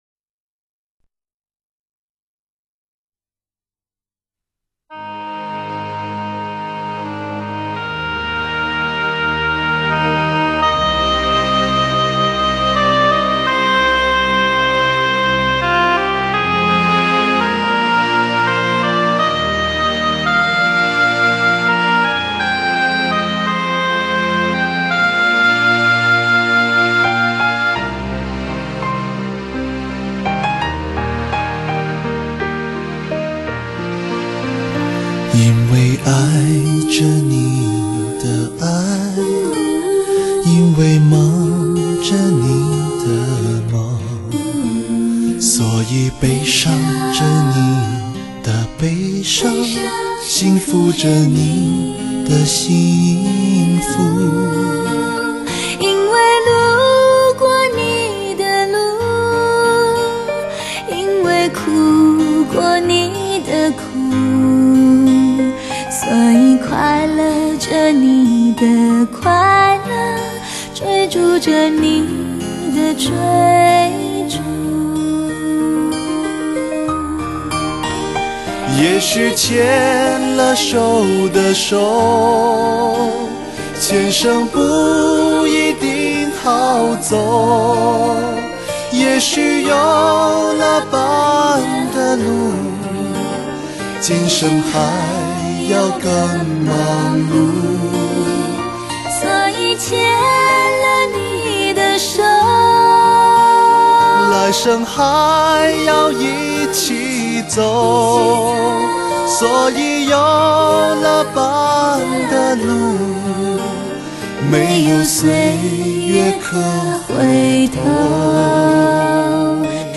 高亢的嗓音，投入的表演，让你得到最完美的身心享受